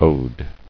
[ode]